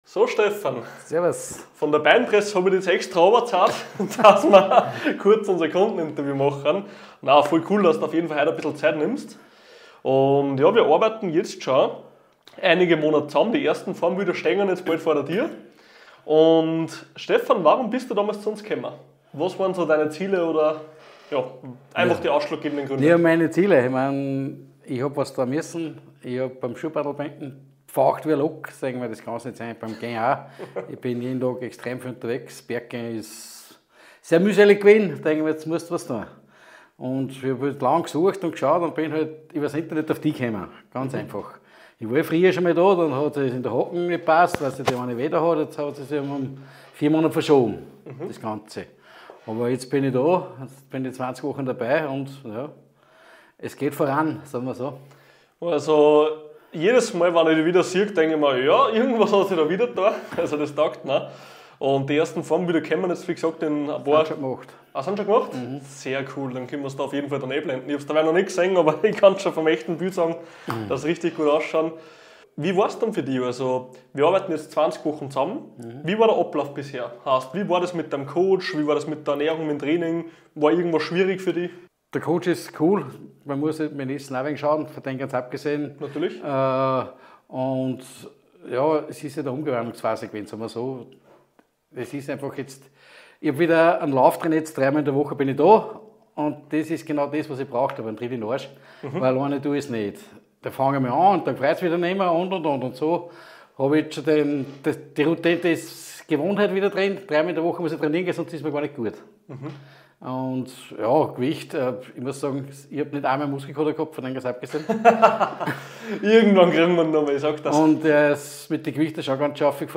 Kundeninterview